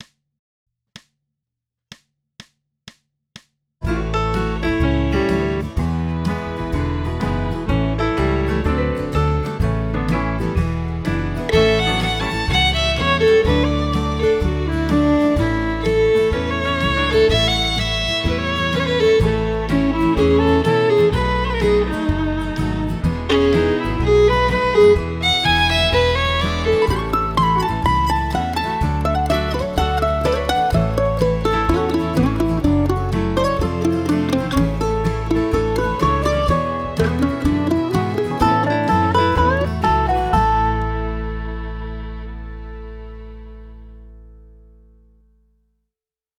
I just entered a chord progression, picked a country style that fit 120 bpm and let RealBand generate the drums, rhythm guitar, bass and piano. I then added a RealTrack track, backing style, with strings.
Last steps were to add individual solo style tracks for fiddle, mandolin and resonator guitar only in selected areas of the song. Ported all these tracks to Reaper, added stereo reverb to the solos. mastered the levels a bit and duped and shifted a few tracks to extend the ending.
Edit: Just remixed it to bring up the piano for the intro, reduced the mandolin reverb and evened out the fiddle part with a volume envelope!